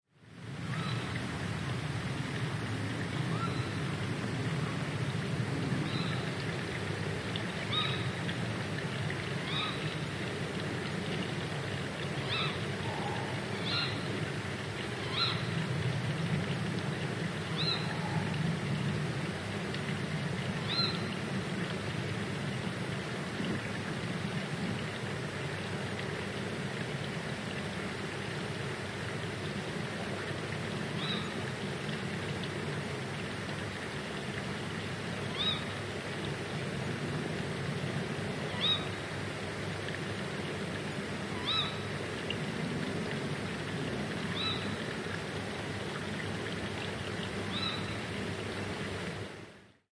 描述：苏格兰西南部Samye Ling藏传佛教中心附近的一小片针叶林里，晚上11点左右，黄褐色的猫头鹰发出'kewick'式的叫声，Samye Ling所在地有小瀑布的声音。 录音。奥林巴斯LS3 + OKM双耳话筒.
Tag: 桑耶寺 - Lings 瀑布 农村 声音 黄褐色猫头鹰 西南 苏格兰